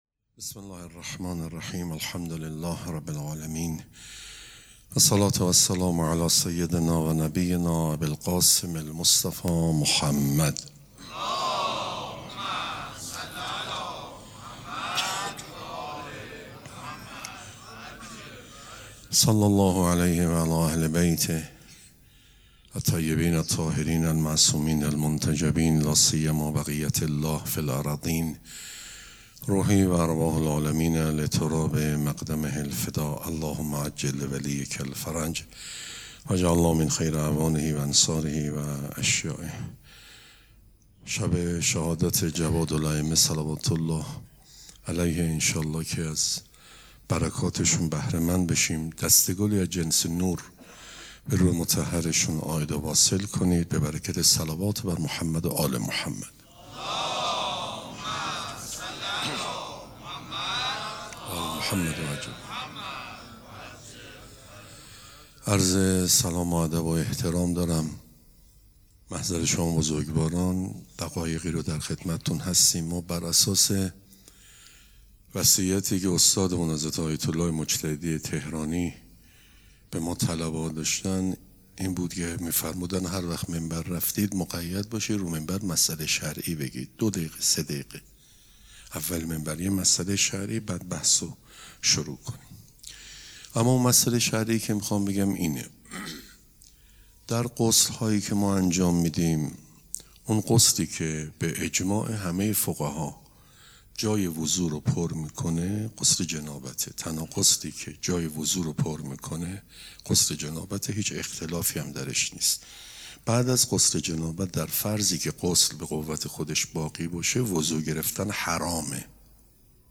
سخنرانی
مراسم عزاداری شهادت امام جواد علیه‌السّلام دوشنبه ۵ خرداد ماه ۱۴۰۴ | ۲۸ ذی‌القعده ۱۴۴۶ حسینیه ریحانه الحسین سلام الله علیها